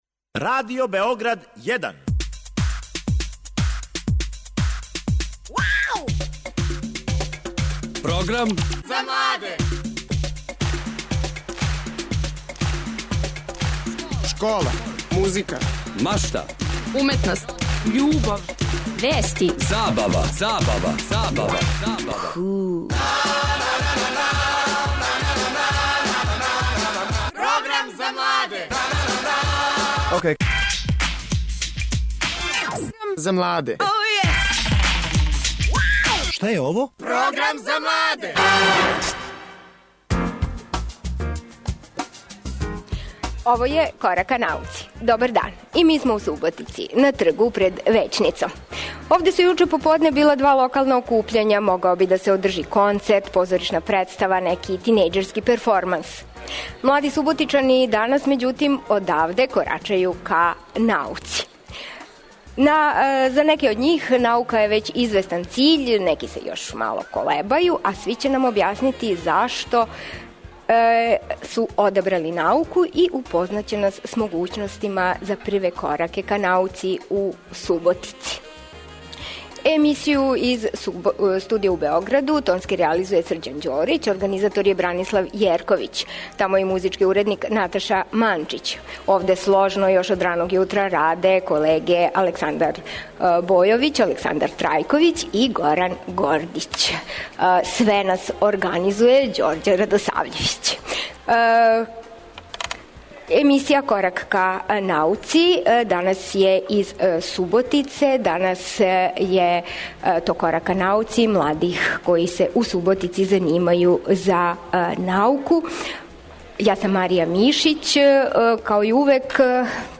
Данашњи 'Корак ка науци' реализује се из Суботице, а гости су млади из Гимназије и Политехничке школе из тог града, који се занимају за науку. Говоримо о условима за бављење науком и у школи и у граду, а с обзиром на то да смо у међународној години хемије, причамо о томе колико су млади хемичари у стању да промене однос према овој науци код својих вршњака.